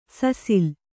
♪ sasil